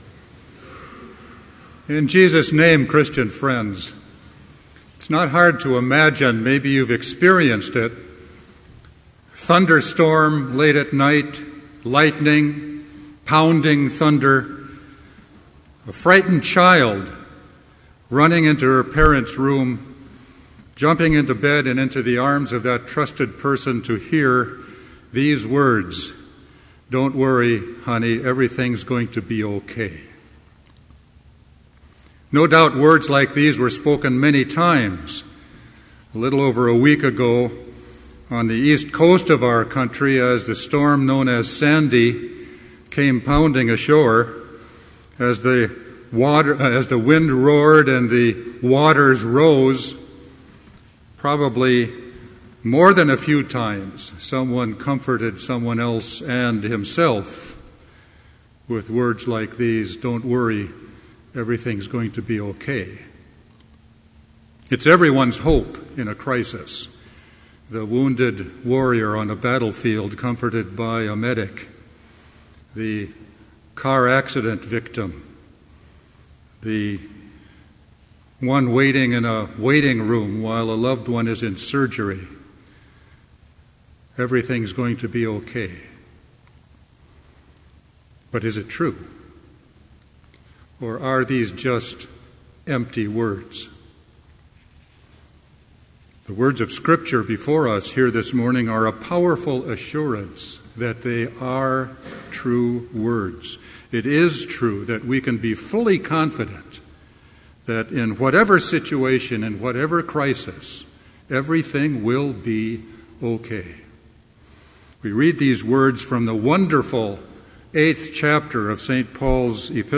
Complete Service
• Prelude
• Hymn 521, vv. 1-3, How Firm a Foundation
• Homily
This Chapel Service was held in Trinity Chapel at Bethany Lutheran College on Thursday, November 8, 2012, at 10 a.m. Page and hymn numbers are from the Evangelical Lutheran Hymnary.